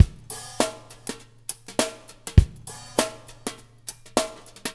BSH DRM+HH-L.wav